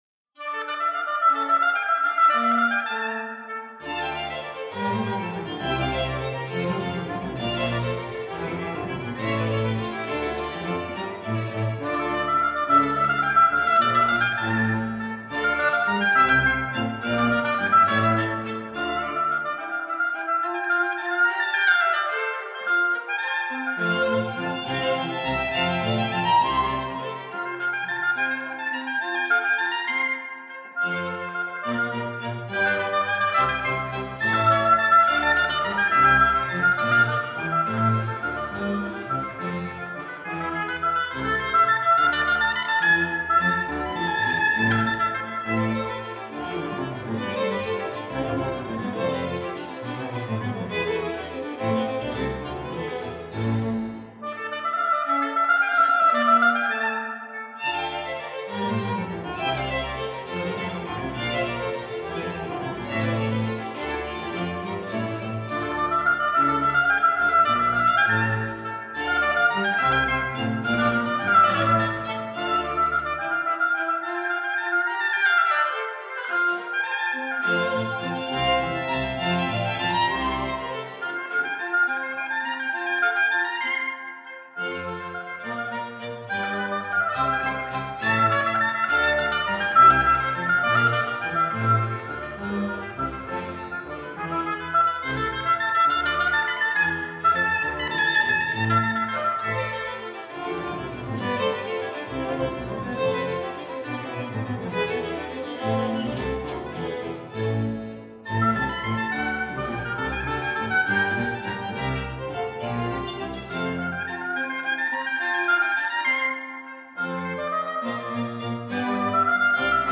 Capella Salonisti (Live Aufnahmen)
Oboe